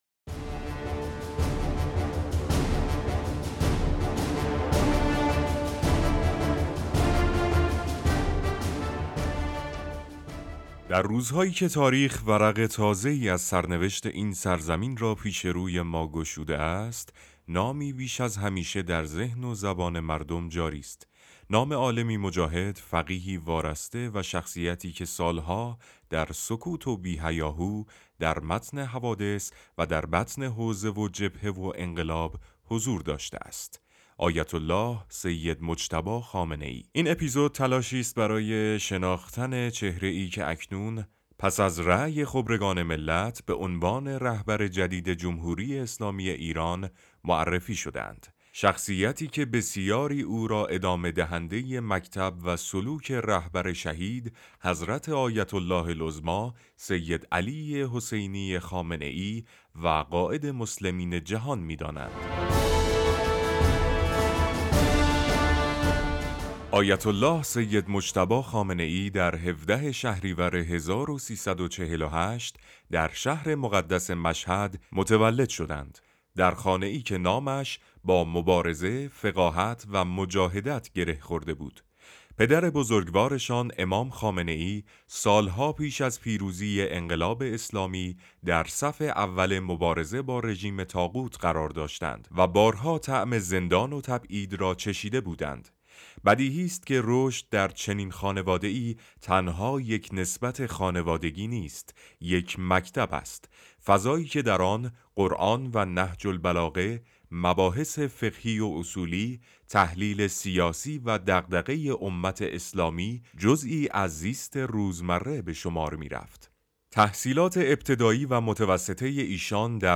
آناکست؛ مستند